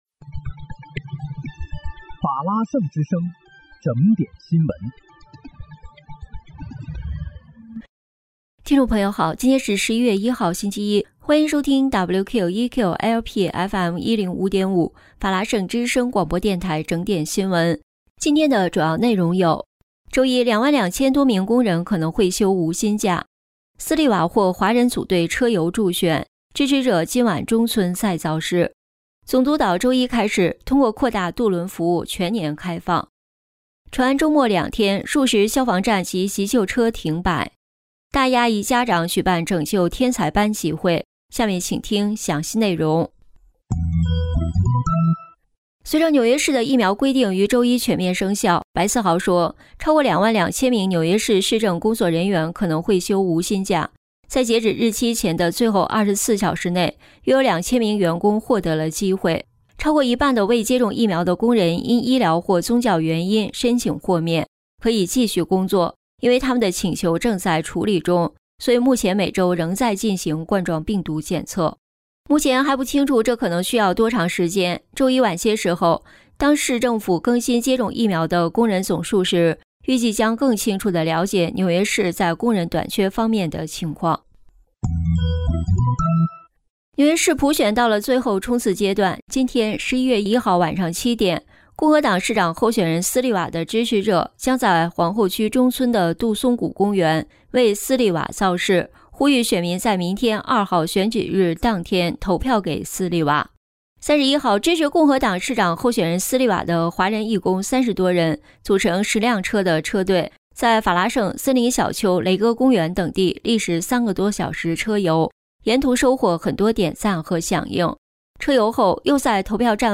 11月1日（星期一）纽约整点新闻